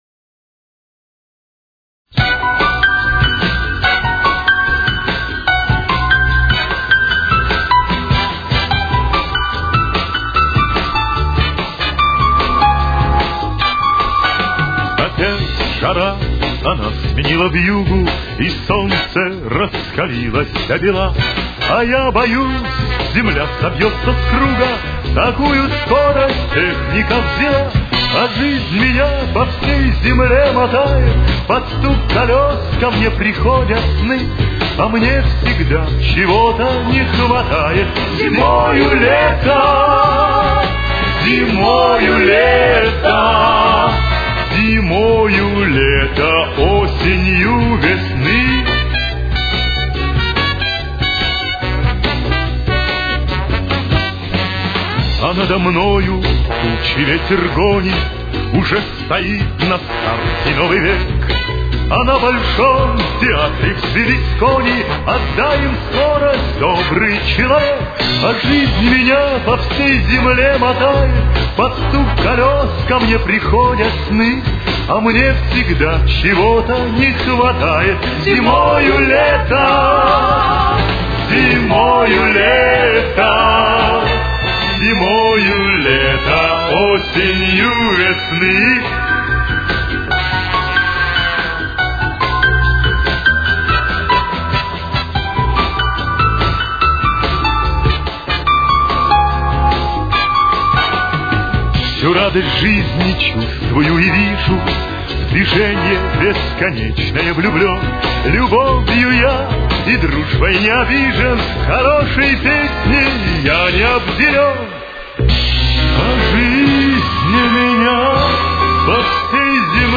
с очень низким качеством (16 – 32 кБит/с).
Темп: 146.